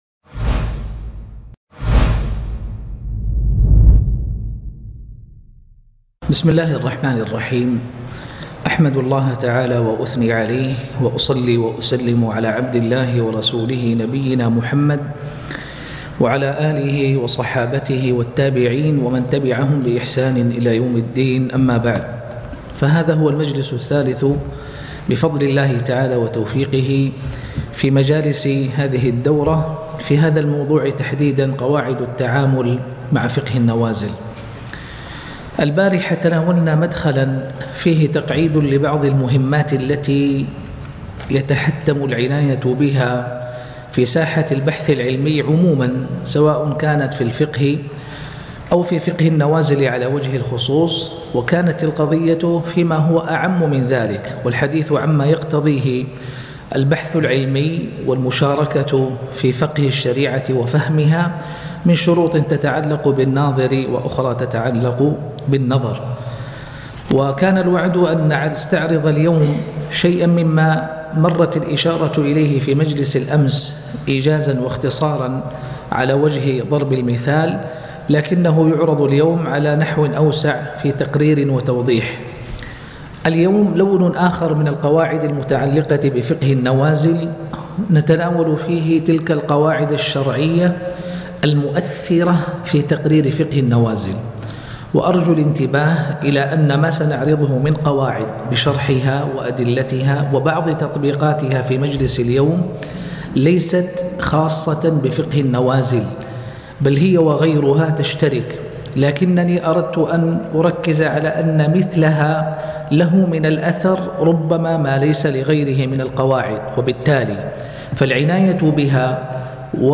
قواعد في فقه النوازل الدرس الثالث